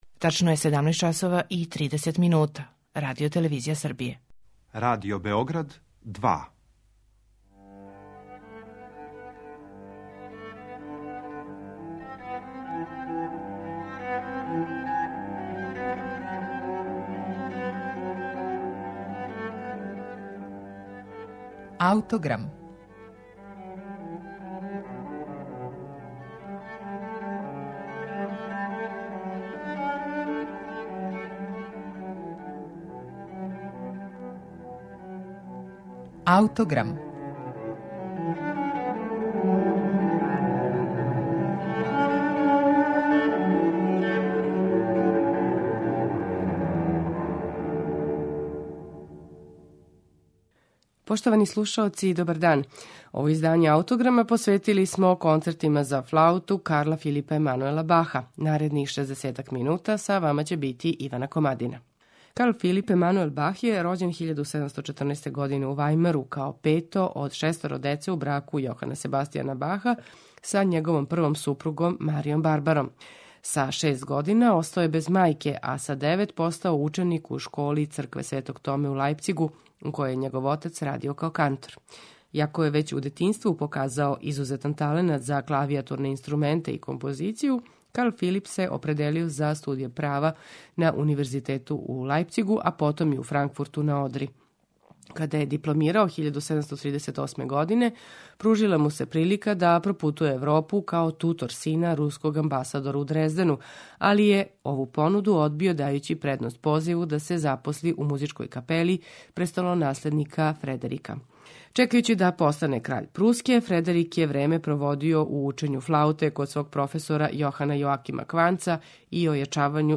Представићемо концерте за флауту, гудаче и харпсикорд у де-молу и Ге-дуру Карла Филипа Емануела Баха